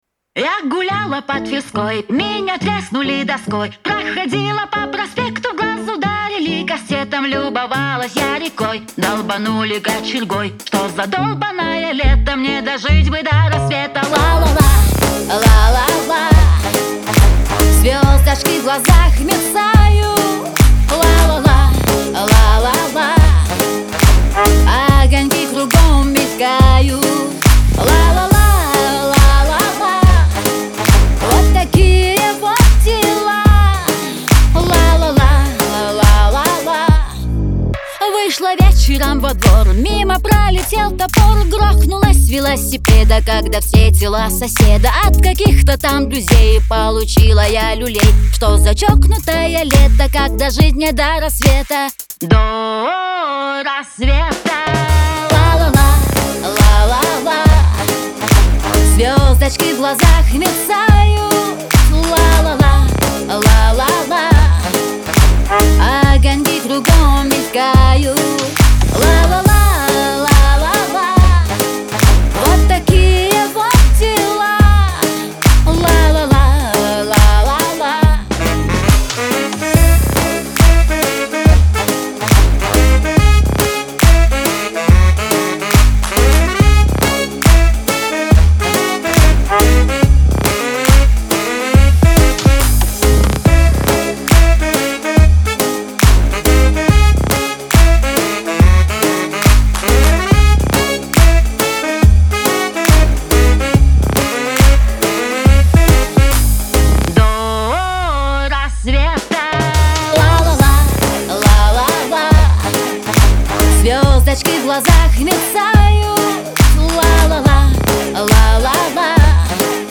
танцы
Веселая музыка , pop